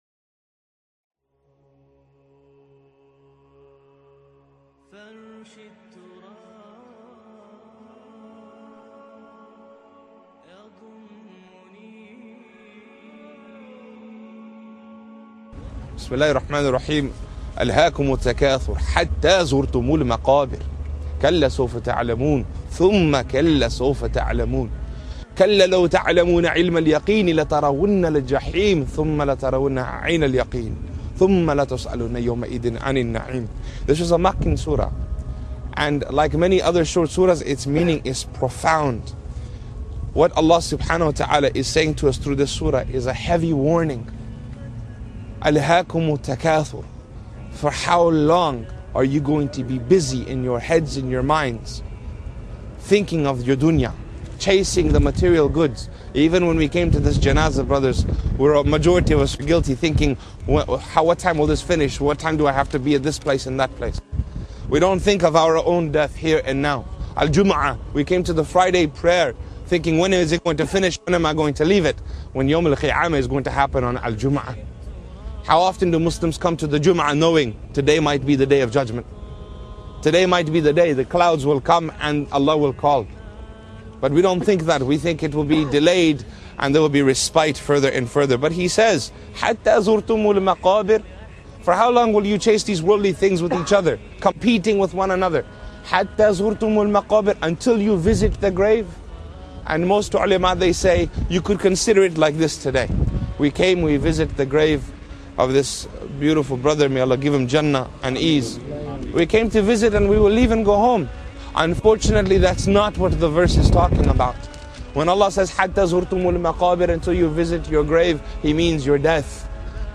Drawing from Surah At-Takathur, this lecture explores how the relentless pursuit of worldly things blinds us to the reality of the grave and the Day of Judgment.